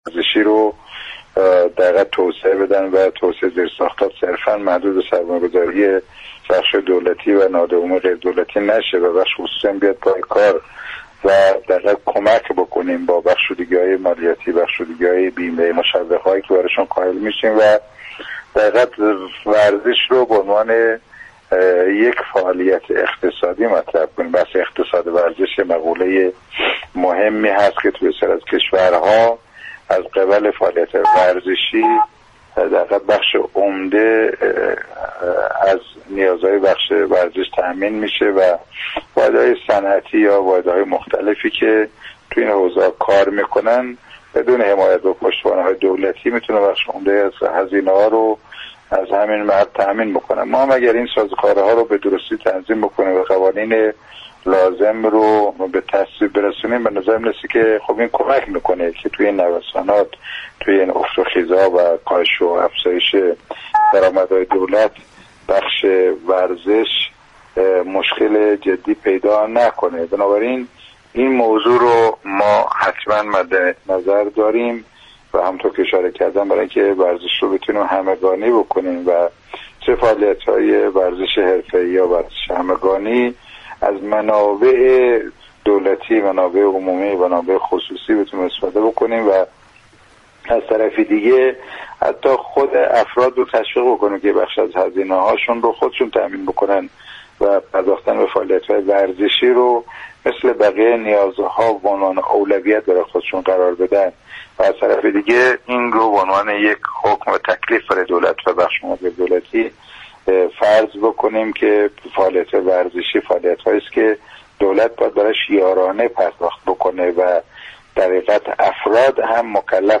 به گزارش رادیو ورزش؛ جعفر قادری، نماینده حوزه انتخابیه شیراز و زرقان و عضو كمیسیون برنامه و بودجه و محاسبات مجلس شورای اسلامی، در خصوص بودجه ورزش در كشور به برنامه "همیشه با ورزش" گفت: ما هم فعالیت های مربوط به ورزش همگانی و هم ورزش قهرمانی را به عنوان بخشی از نیازهای جامعه می دانیم كه حتماً‌ باید از منابع عمومی، منابع مربوط به شركت های دولتی و منابعی كه به عنوان عوارض ارزش افزوده مالیات جمع آوری می شود، سهم ببرد.